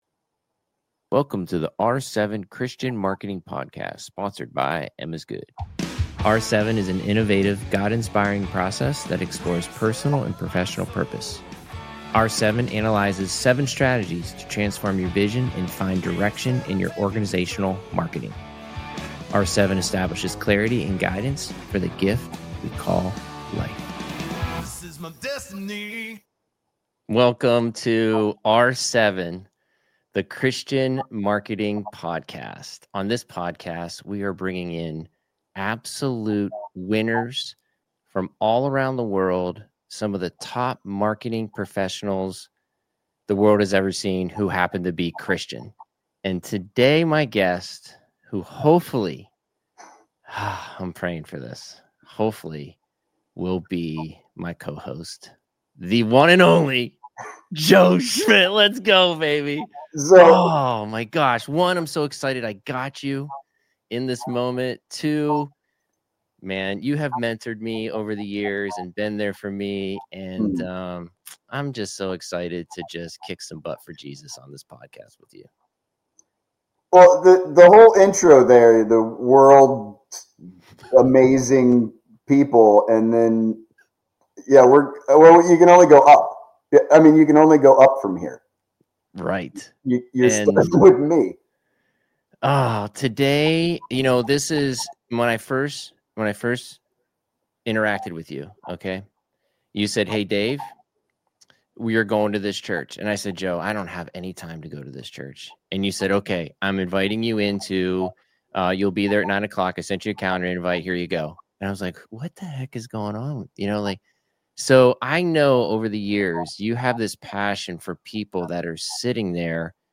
This raw, honest discussion redefines what it means to live out your purpose with stellar effort, not perfect outcomes.